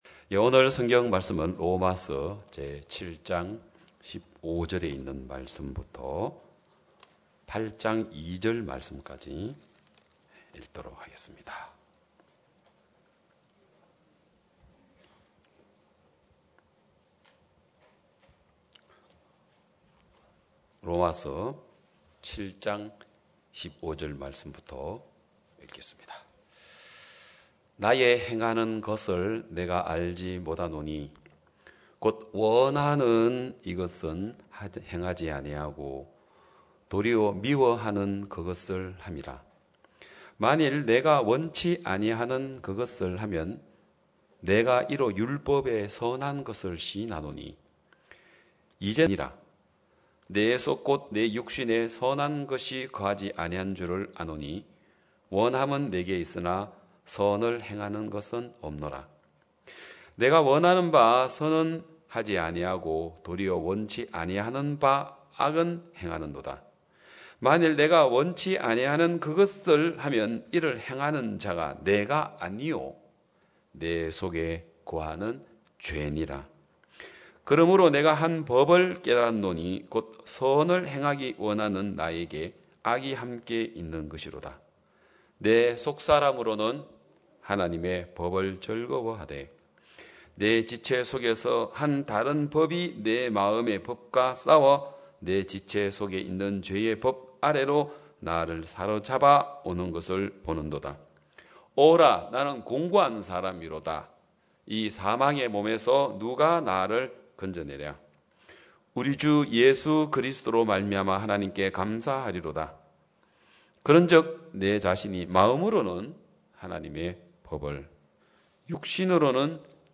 2021년 5월 23일 기쁜소식양천교회 주일오전예배
성도들이 모두 교회에 모여 말씀을 듣는 주일 예배의 설교는, 한 주간 우리 마음을 채웠던 생각을 내려두고 하나님의 말씀으로 가득 채우는 시간입니다.